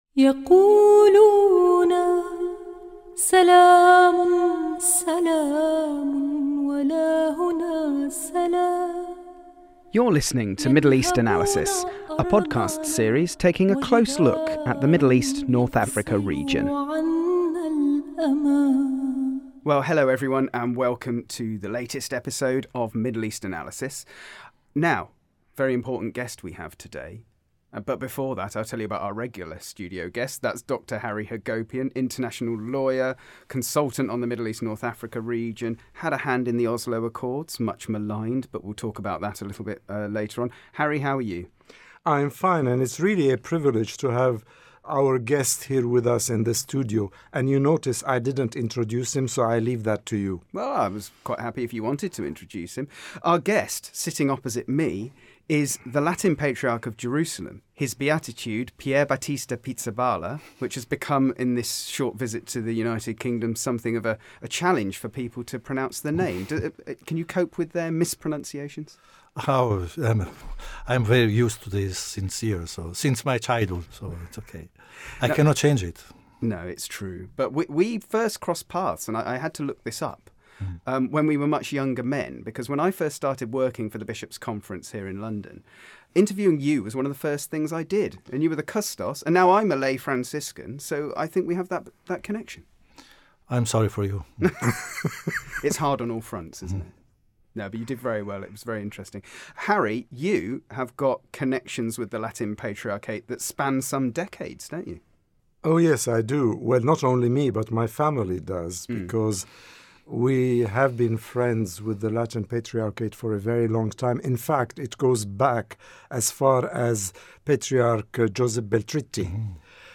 Our studio guest for this special 30-minute Catholic News podcast is the Latin Patriarch of Jerusalem, His Beatitude Cardinal Pierbattista Pizzaballa.